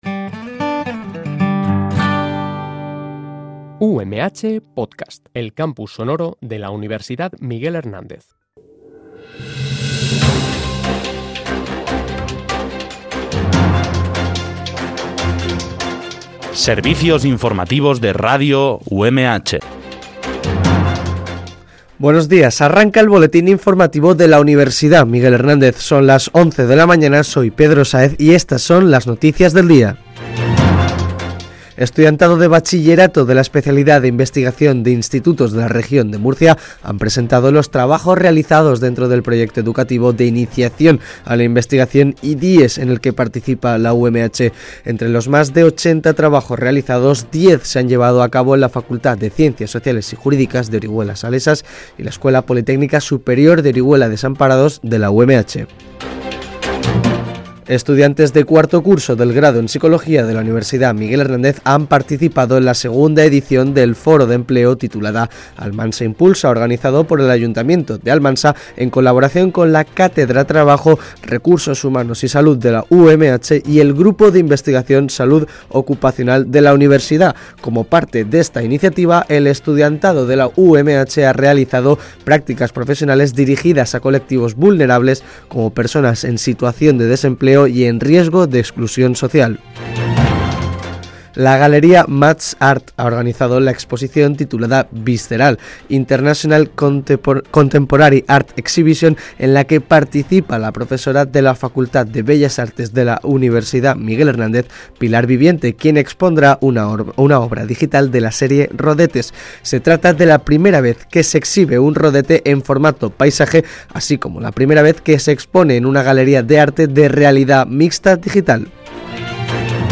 BOLETÍN INFORMATIVO UMH